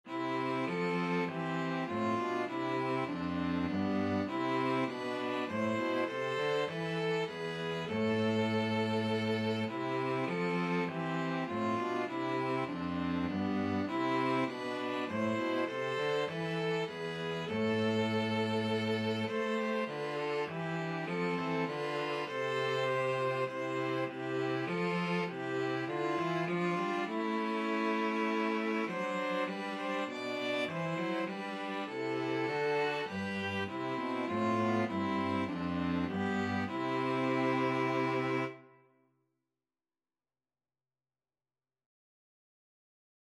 Free Sheet music for String trio
ViolinViolaCello
4/4 (View more 4/4 Music)
C major (Sounding Pitch) (View more C major Music for String trio )
Classical (View more Classical String trio Music)